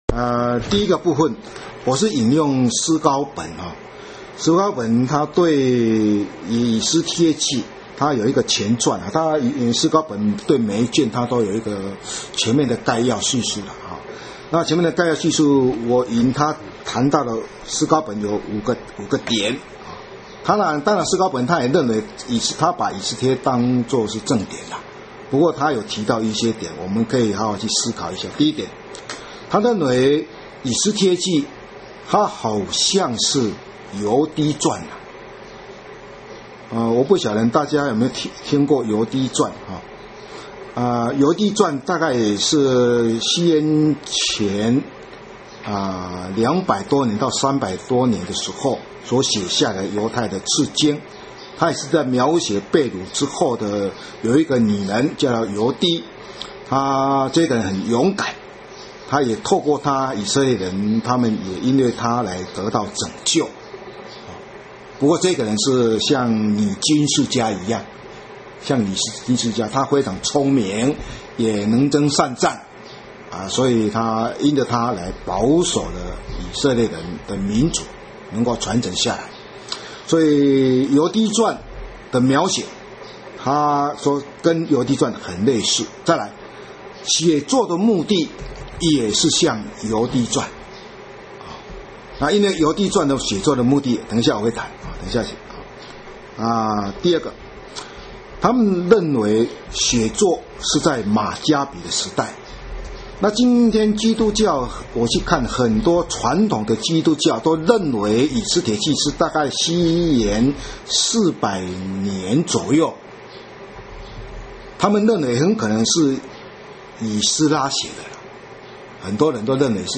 地點：嘉義